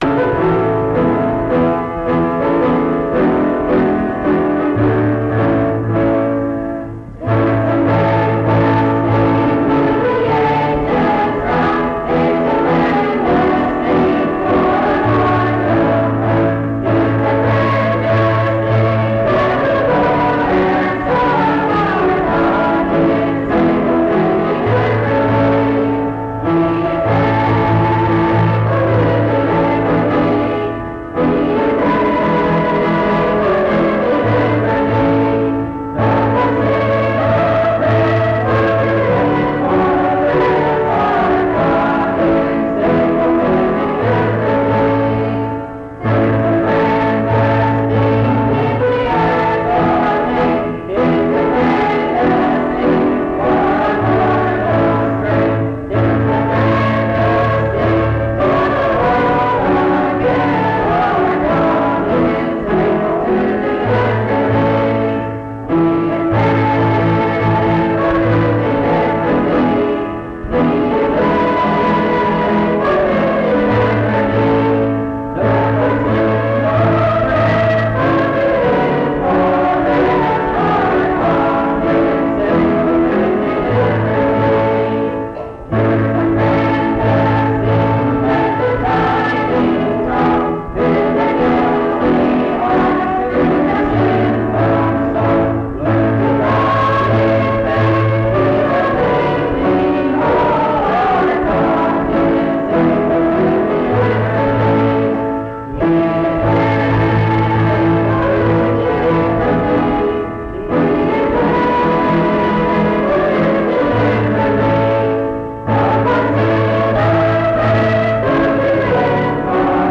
Tis the Greatest Theme Item dbb623a5f5740b41a6fc2ffad9e57d81c0f1d082.mp3 Title Tis the Greatest Theme Creator Mount Union II Choir Description This recording is from the Monongalia Tri-District Sing.